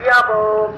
Idiyappam Vendor Sound Button - Free Download & Play